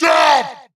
bigLogCutVoice.wav